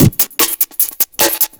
ELECTRO 04-R.wav